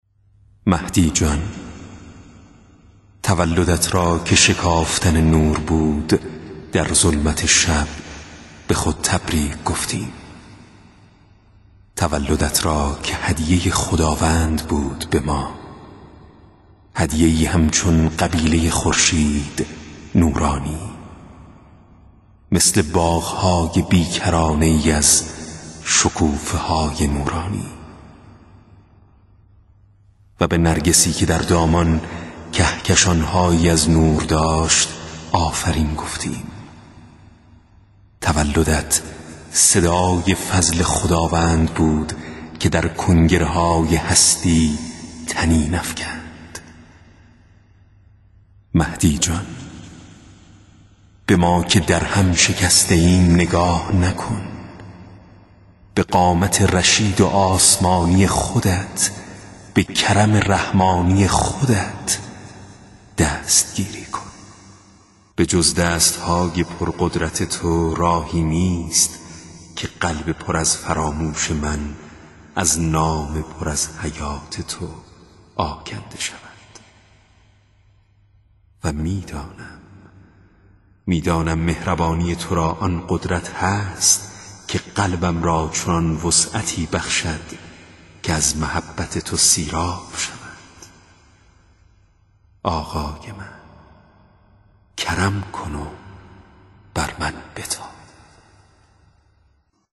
دکلمه‌های مهدوی